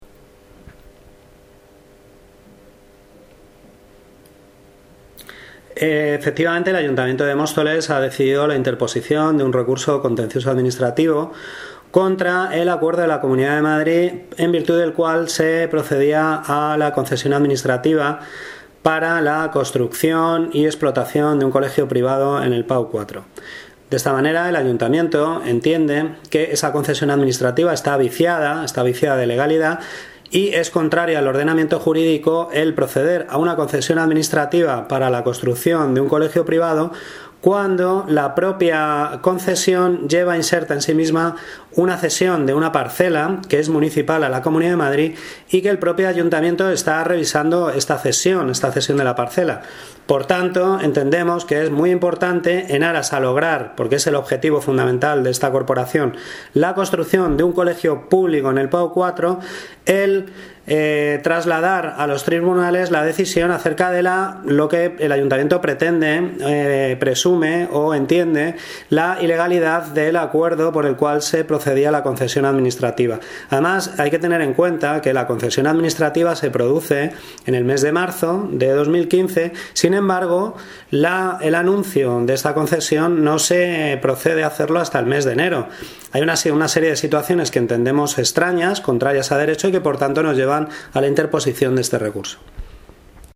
Audio - Eduardo Gutiérrez (Concejal de Urbanismo y Vivienda) Sobre recurso PAU 4